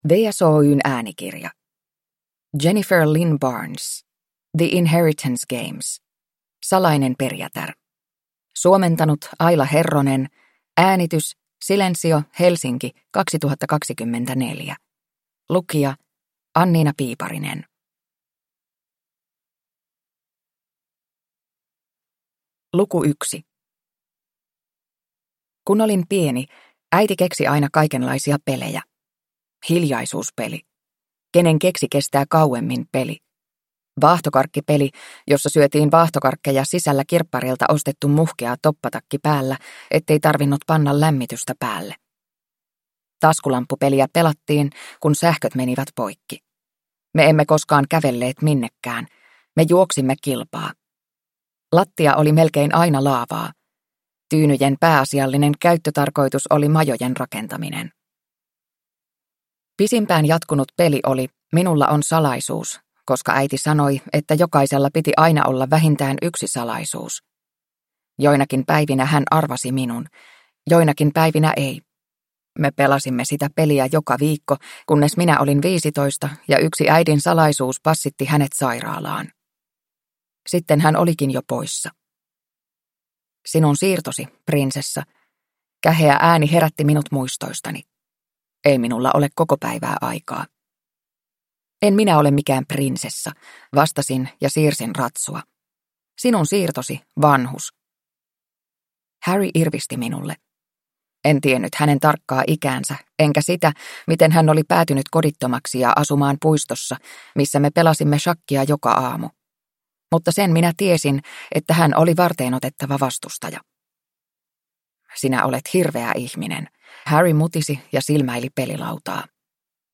The Inheritance Games: Salainen perijätär (ljudbok) av Jennifer Lynn Barnes